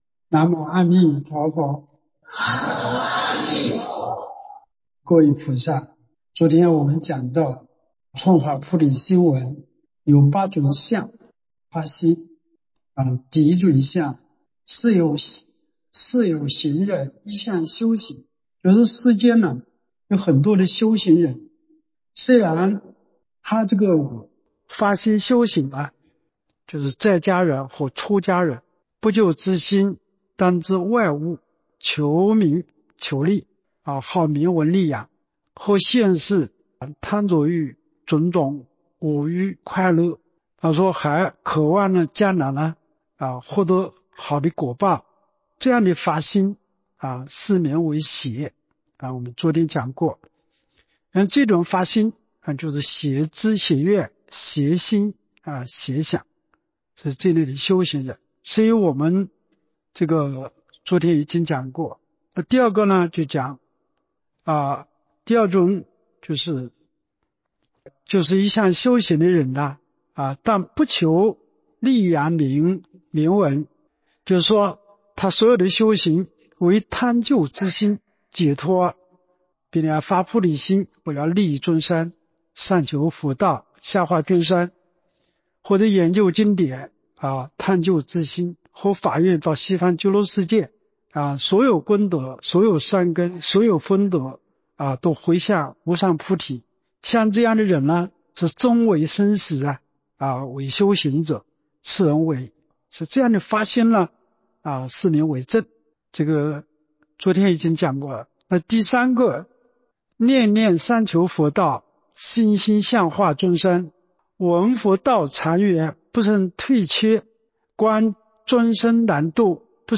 彭泽集福寺佛七开示